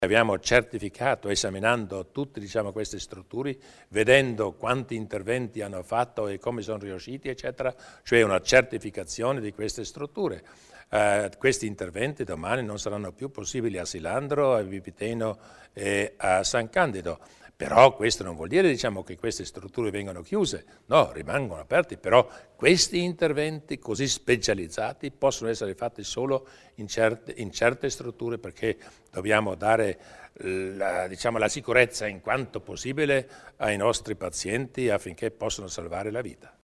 Il Presidente Durnwalder illustra i modelli di certificazione ospedaliera